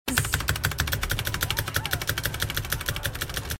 Glitteralerts Keyboard